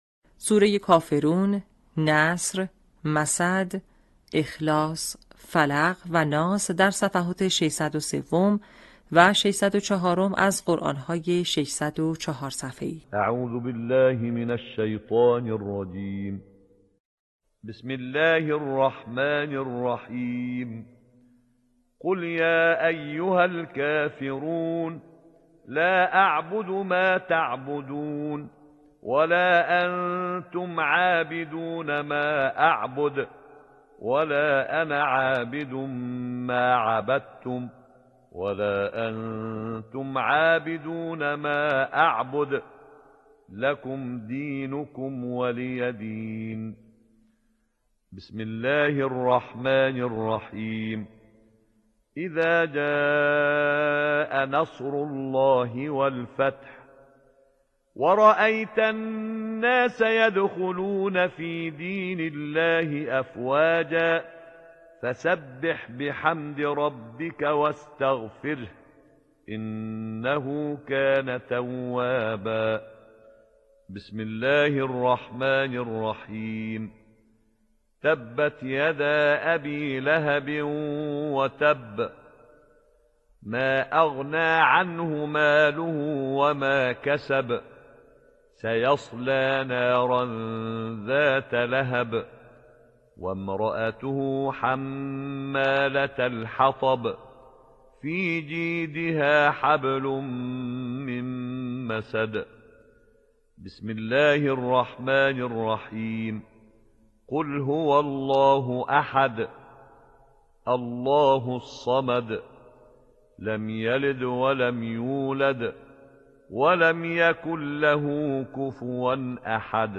به همین منظور مجموعه آموزشی شنیداری (صوتی) قرآنی را گردآوری و برای علاقه‌مندان بازنشر می‌کند.
آموزش حفظ 30 جزء، سوره کافرون تا ناس